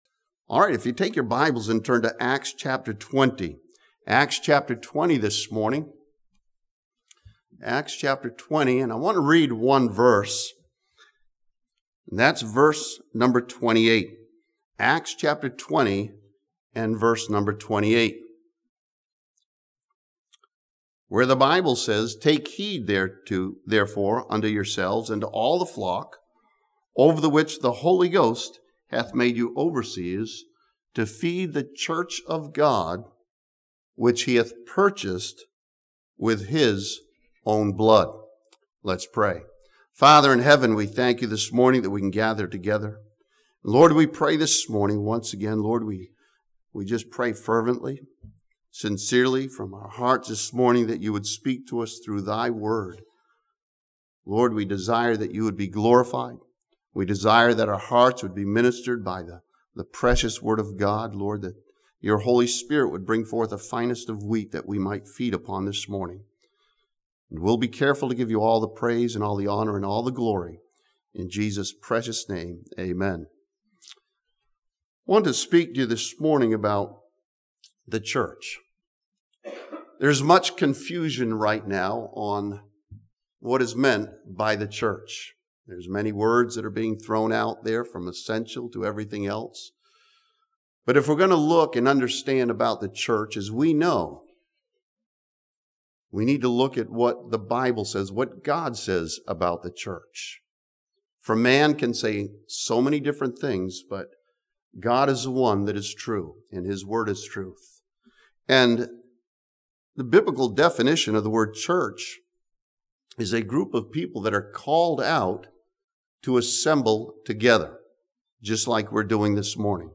This sermon from Acts chapter 20 instructs believers on God's definition of the church.